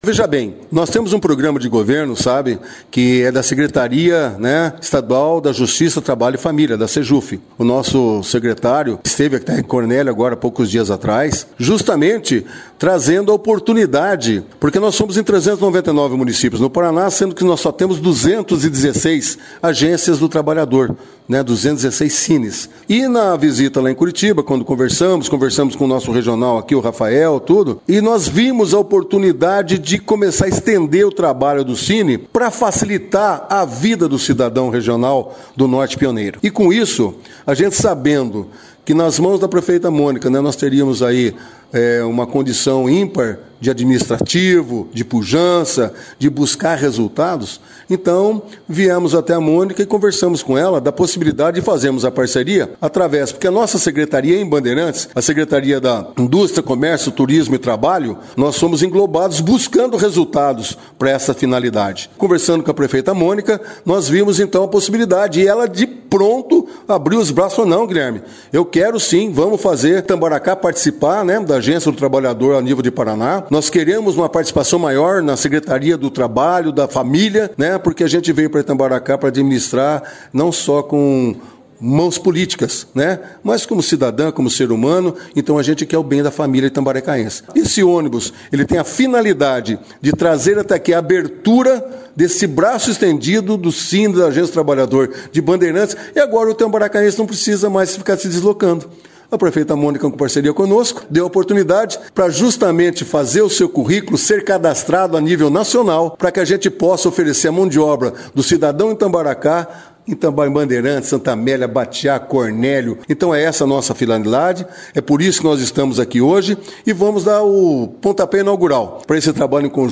Como parte das ações do programa “Emprega Mais Paraná” da Secretaria de Justiça, Família e Trabalho do Paraná, em parceria com a Prefeitura Municipal de Itambaracá e Agência do Trabalhador (Sine de Bandeirantes), o ônibus Itinerante da Agência do Trabalhador, está em na cidade, com o objetivo de proporcionar oportunidades de vagas de empregos disponíveis na região e atualização de cadastro para ajudar o cidadão a conseguir um trabalho! Acompanhamos o início dos trabalhos do ônibus que foi destaque da 1ª edição do jornal Operação Cidade desta terça-feira, 21